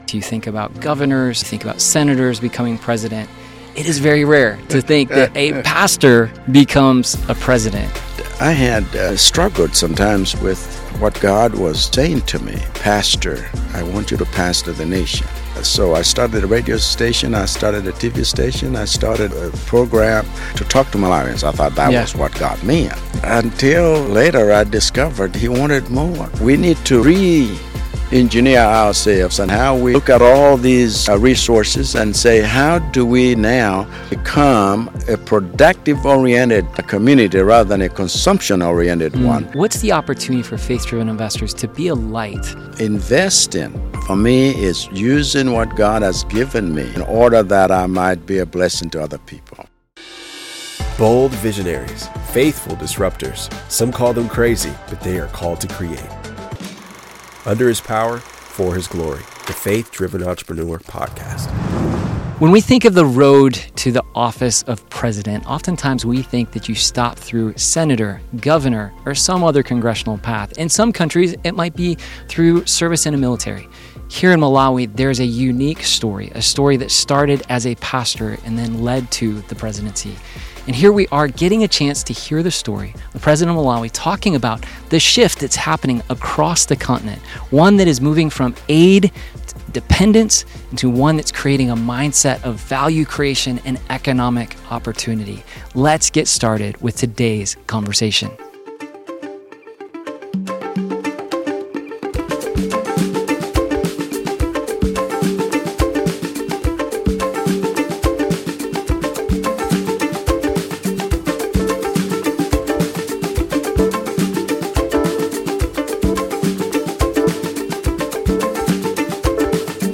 from a stunning lakeside location in Malawi